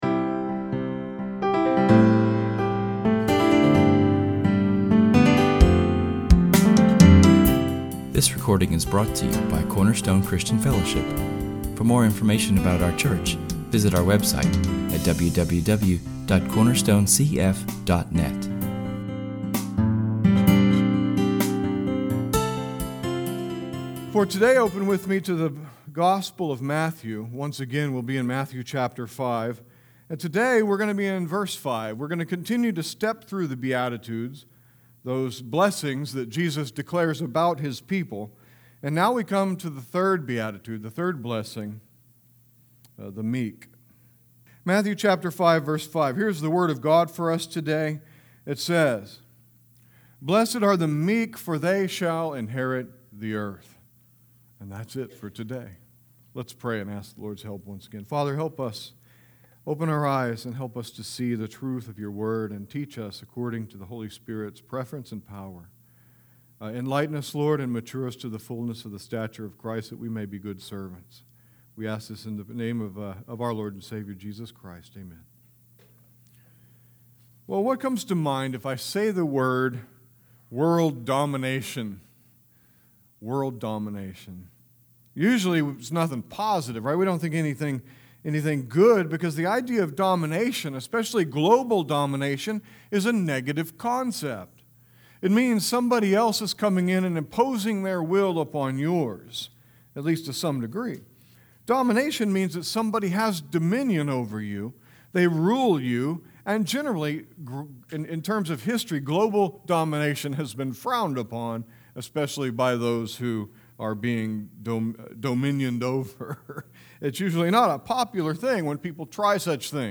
This Lord’s Day we get to look at [esvignore]Matthew 5:5[/esvignore], the beatitude of meekness. How on earth do the meek gain the whole earth by inheritance, and what is it to be meek?